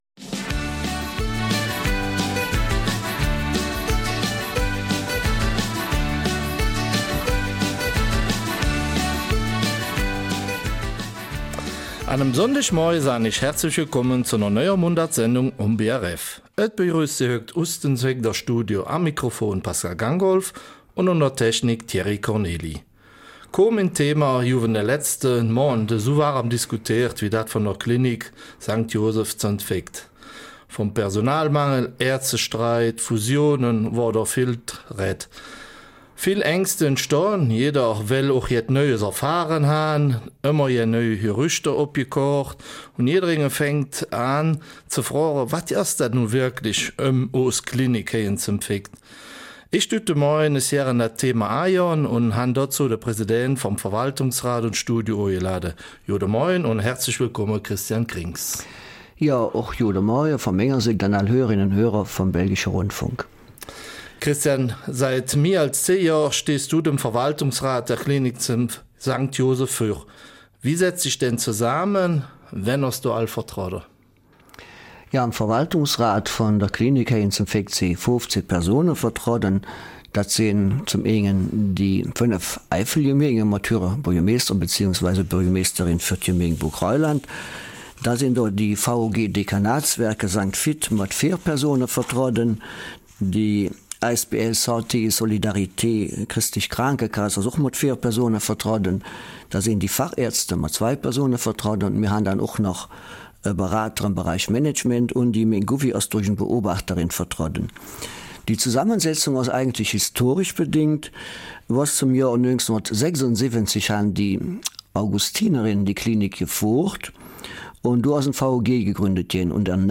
Eifeler Mundart: Klinik St. Josef St.Vith